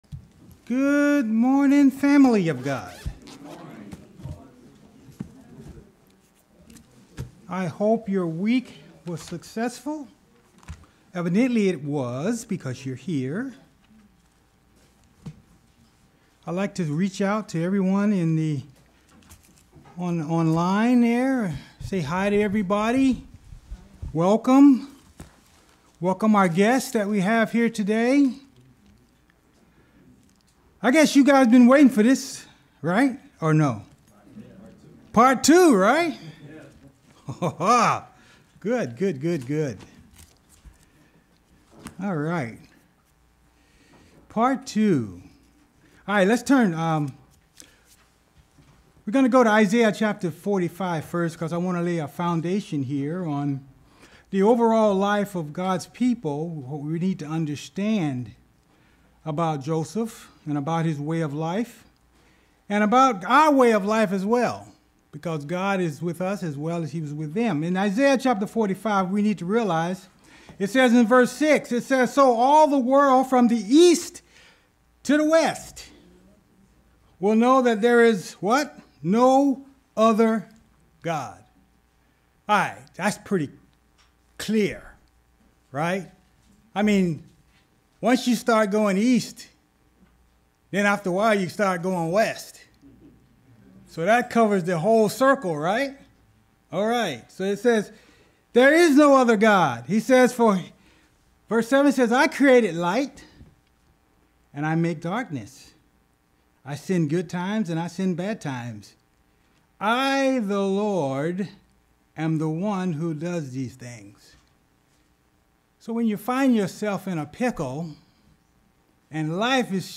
Given in Ft. Lauderdale, FL